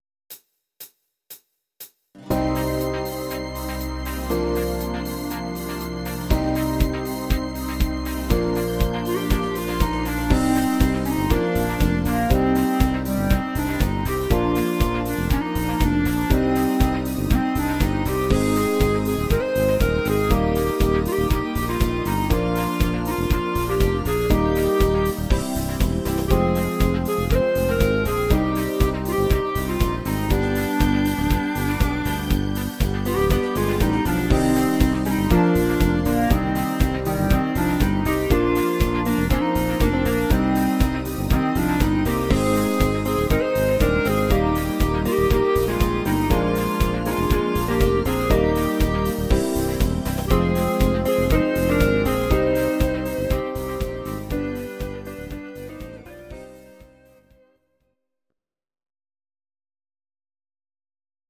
These are MP3 versions of our MIDI file catalogue.
Please note: no vocals and no karaoke included.
instr. Klarinette+Gitarre